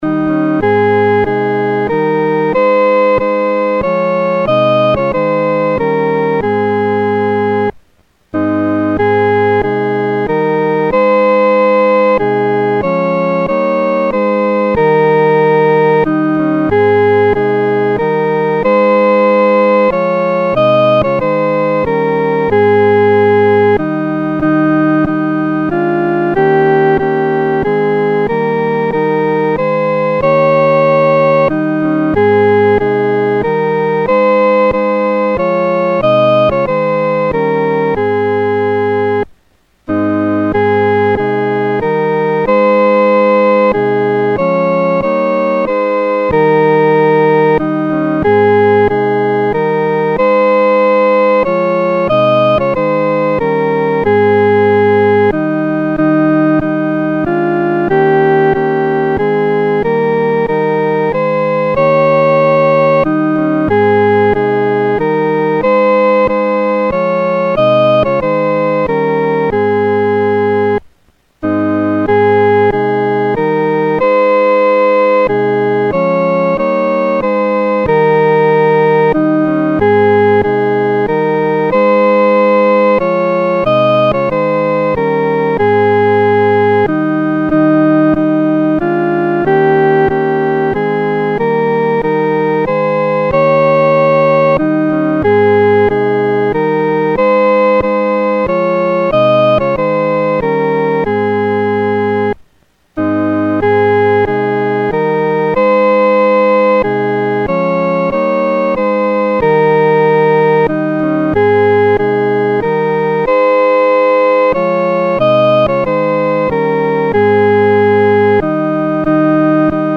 独奏（第一声）
来敬拜荣耀王-独奏（第一声）.mp3